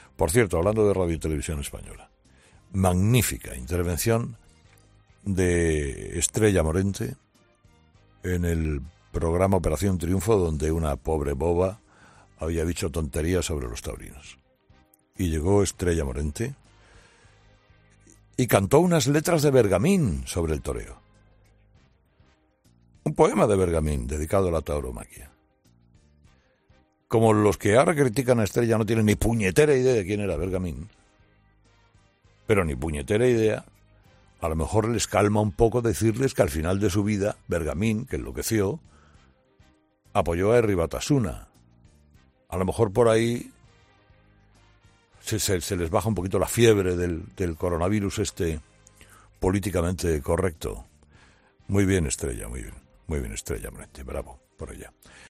En su monólogo de este martes, Carlos Herrera ha salido en defensa de Estrella Morente.